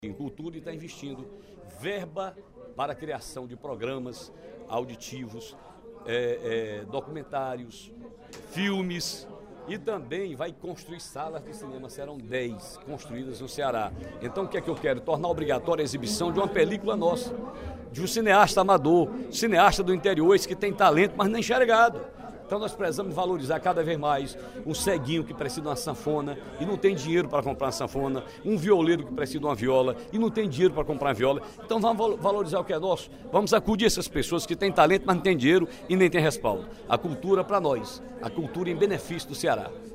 O deputado Ferreira Aragão (PDT) informou, durante o primeiro expediente da sessão plenária desta terça-feira (16/05), que vai apresentar projeto de lei no qual destina um terço dos recursos públicos para produção cultural aos artistas do Interior.